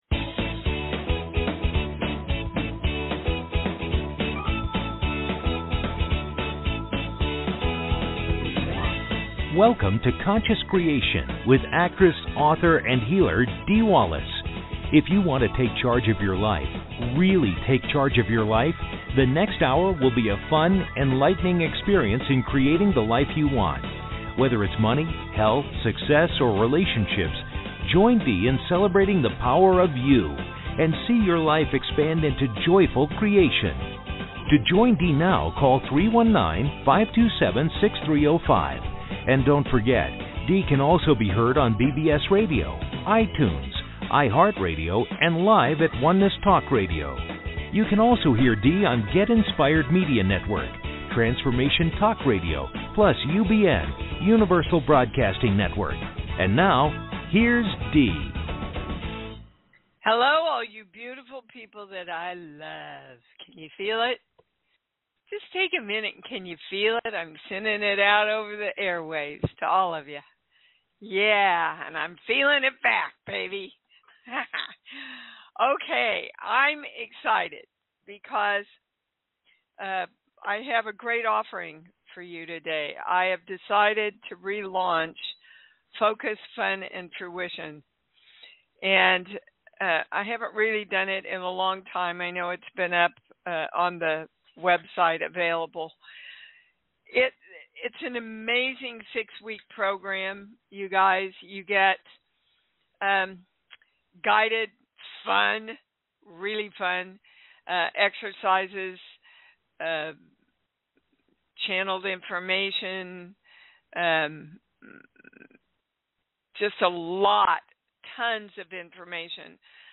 Talk Show Episode, Audio Podcast, Conscious Creation and with Dee Wallace on , show guests , about Dee Wallace,conscious creation,I am Dee Wallace, categorized as Kids & Family,Philosophy,Psychology,Self Help,Society and Culture,Spiritual,Access Consciousness,Medium & Channeling,Psychic & Intuitive